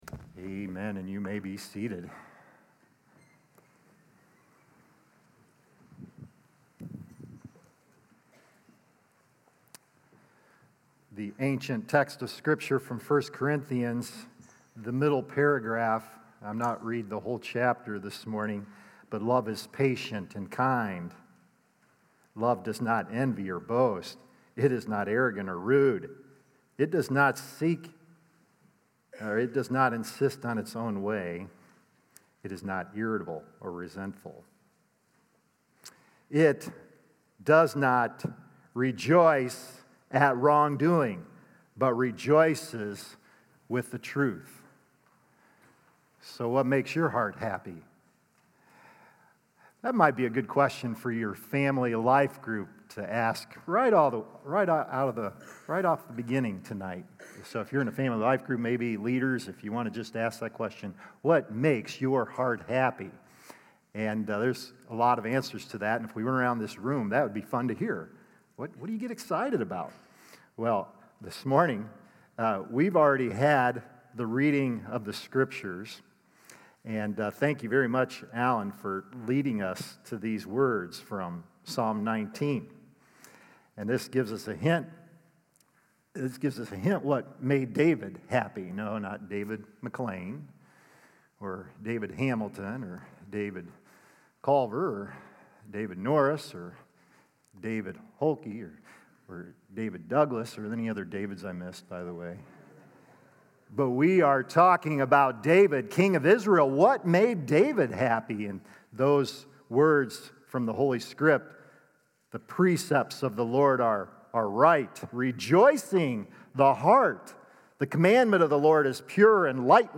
Love Knows How To Party | Baptist Church in Jamestown, Ohio, dedicated to a spirit of unity, prayer, and spiritual growth